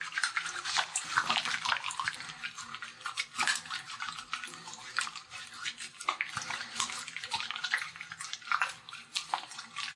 洗手间 " 02洗手液
描述：有肥皂的肥皂手，关闭。